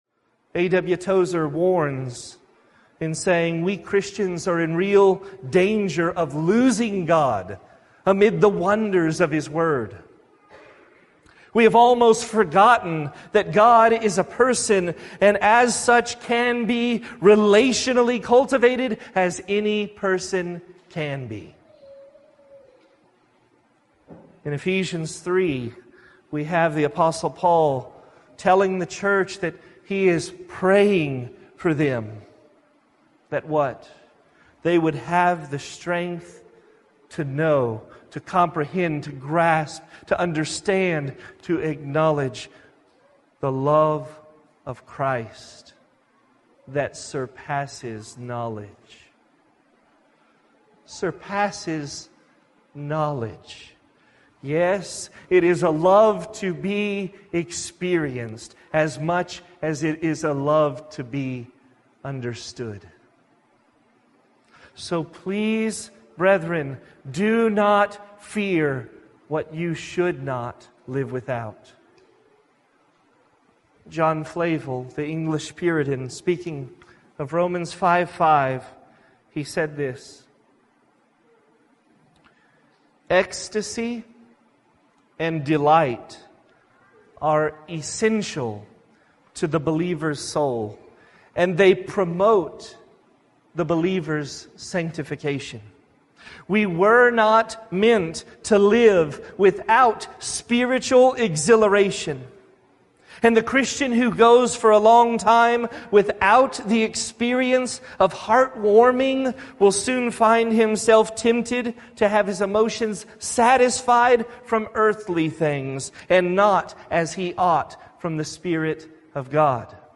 2020 Category: Excerpts We won’t last long as Christians without experiencing the love and reality of Christ.